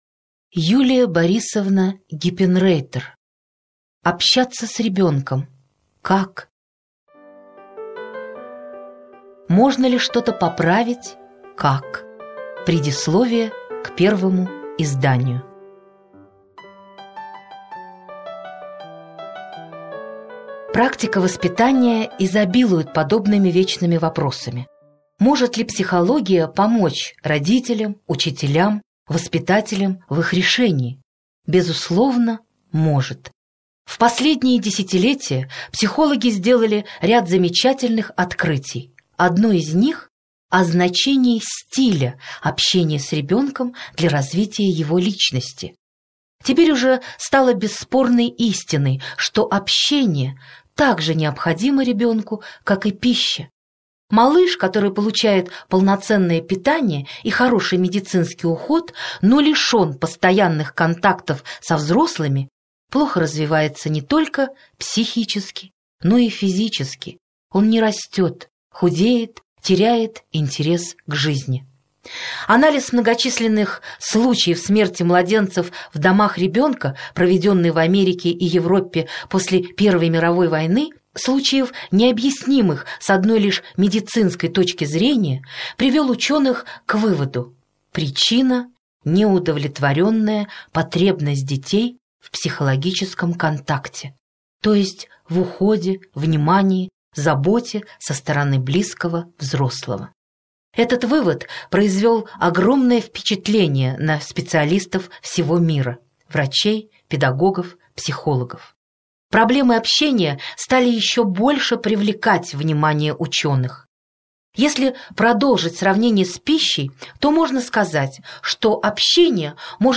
Аудиокнига Общаться с ребенком.